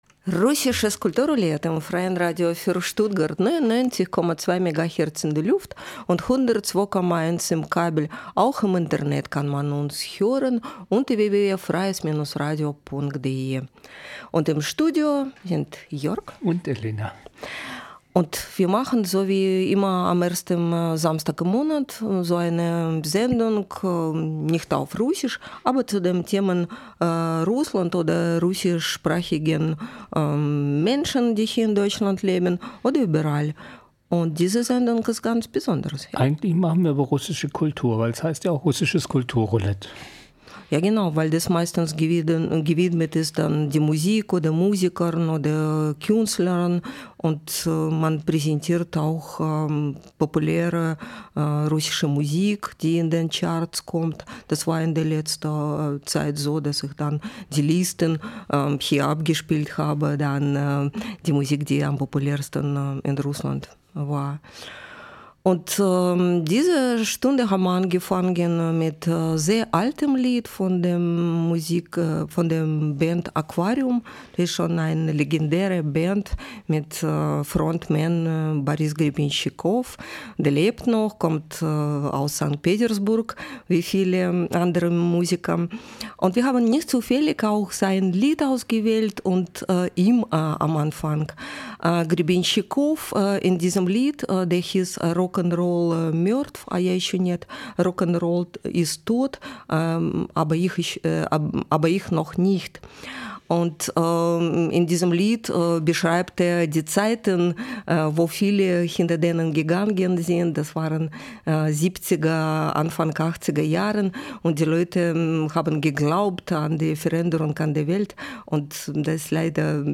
In diesem Radiobeitrag berichten wir über zwei verschiedene Einrichtungen im West und Ost, ohne sie richtig zu vergleichen.